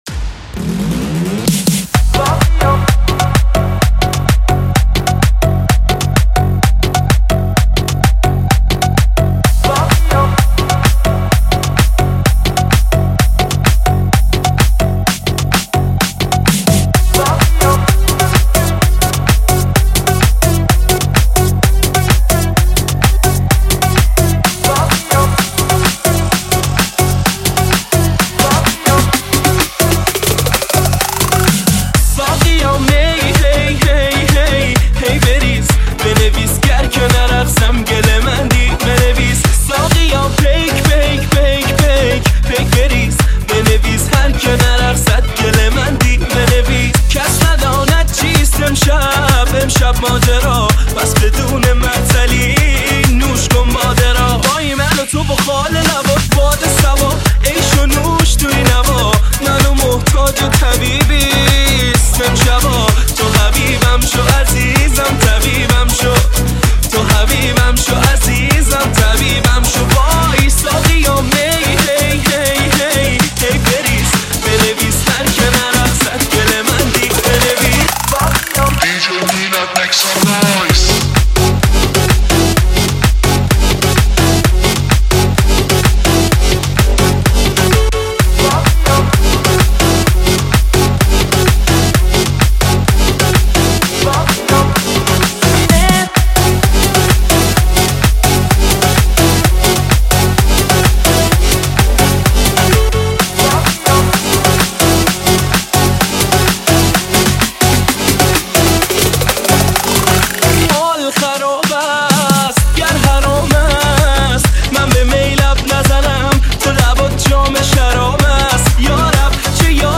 ریمیکس ارکستی شاد
ریمیکس شاد رقص و دنس
ریمیکس شاد و بندری مخصوص رقص